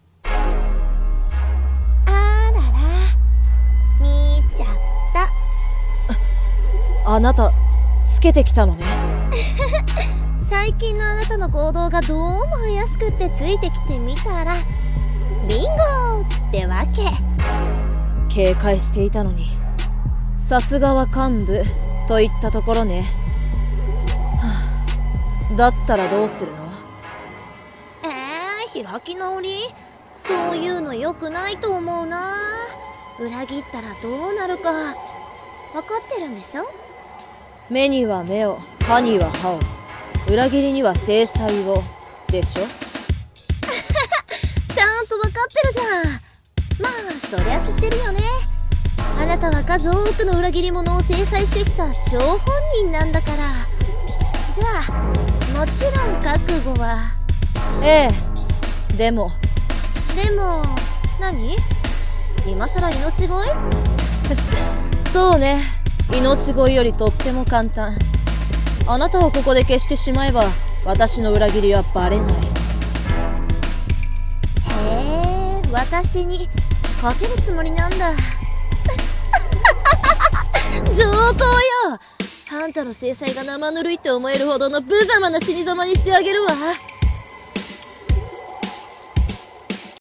【声劇】裏切りには制裁を【掛け合い】